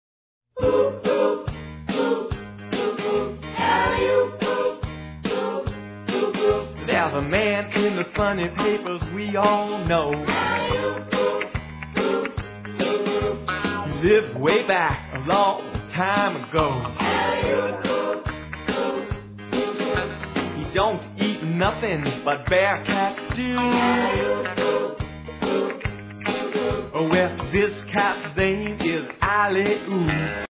excellent rock 'n' roll with wacky skits